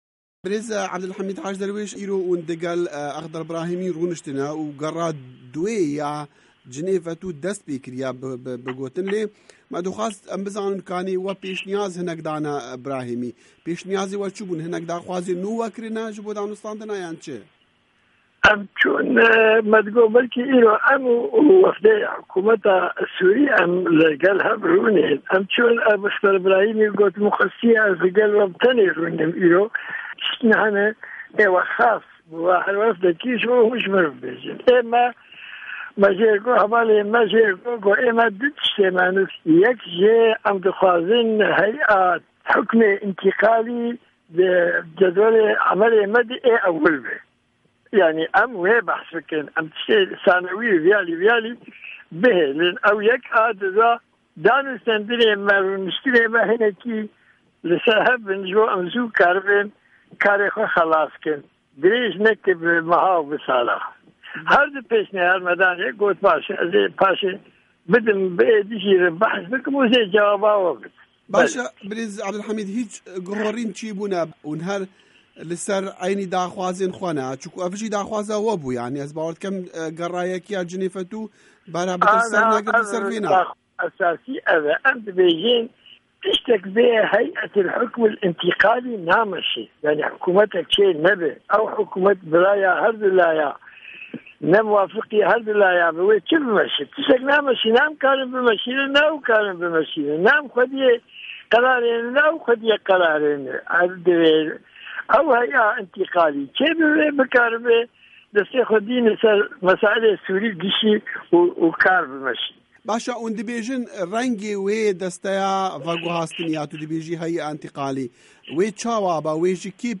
Hevpeyivin